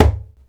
DJEMBE 1A.WAV